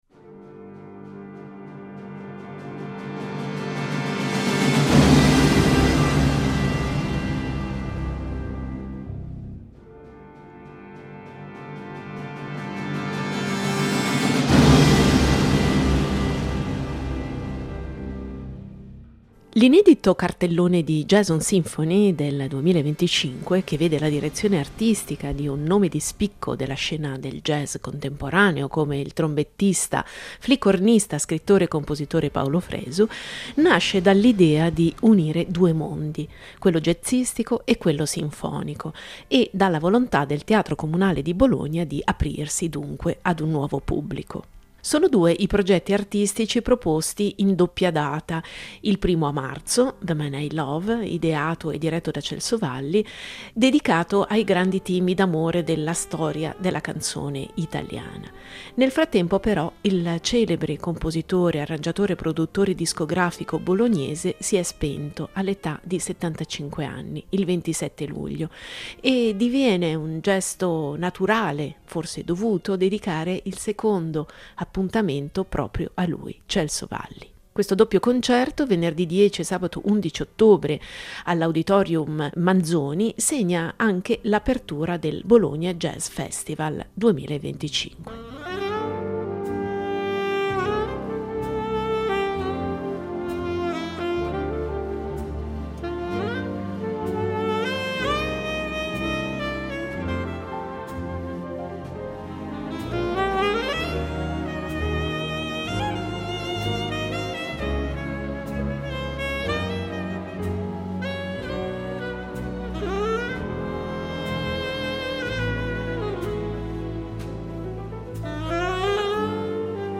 Il teatro Comunale di Bologna rilegge il jazz nella storia del cinema con gli inediti arrangiamenti di Vince Mendoza